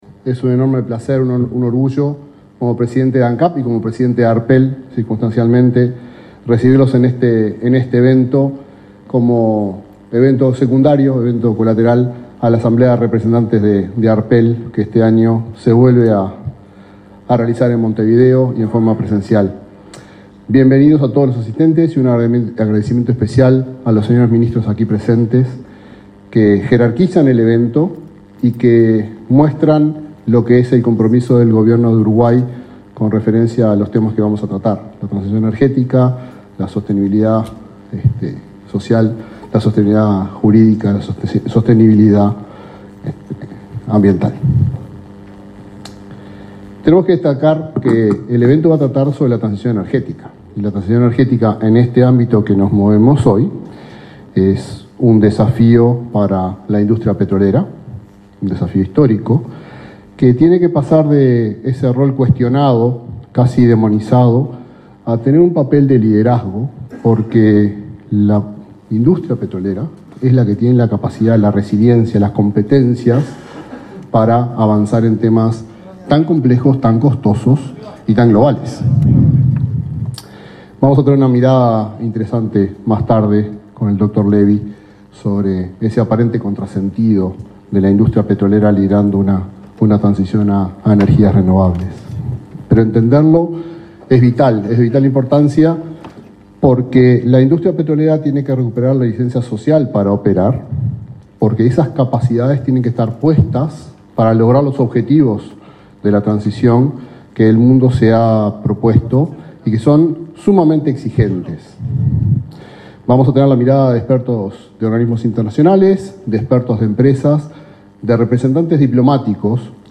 Palabras del presidente de Ancap, Alejandro Stipanicic
El presidente de Ancap, Alejandro Stipanicic, abrió una conferencia sobre transiciones energéticas, realizada este miércoles 26 en Montevideo.